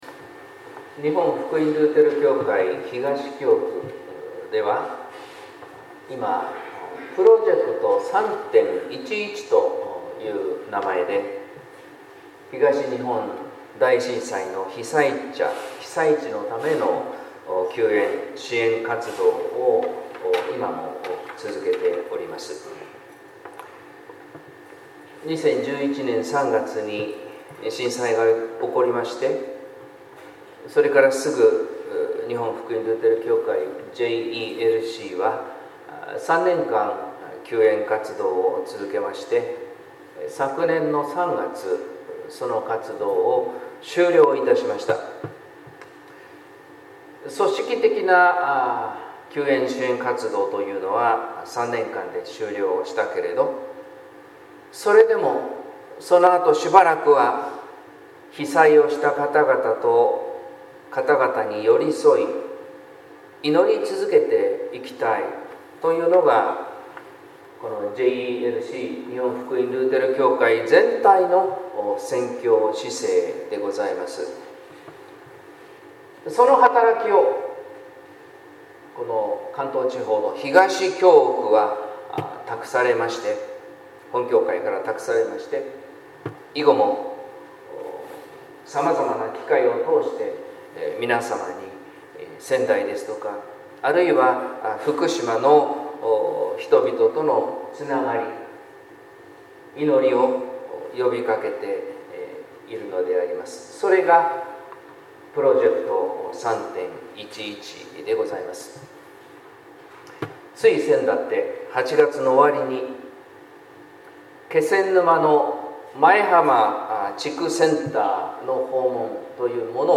説教「ことばが救う」（音声版）